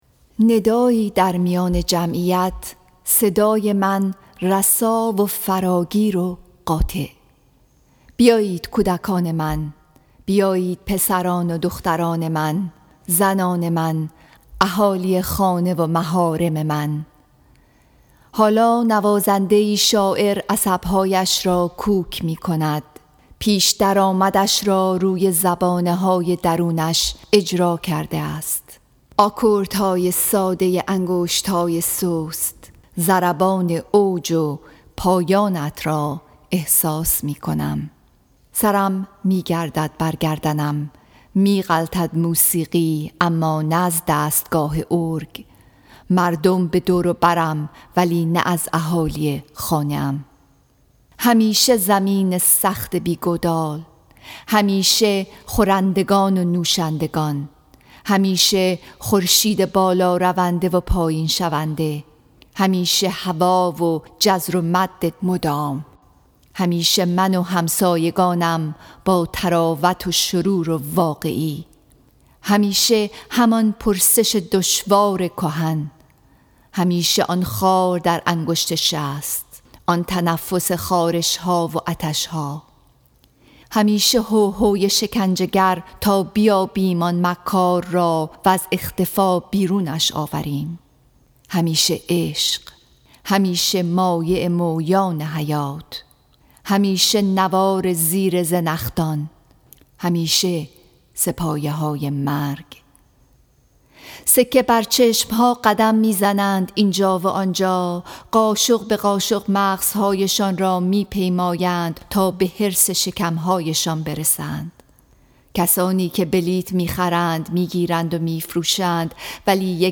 Song of Myself, Section 42 —poem read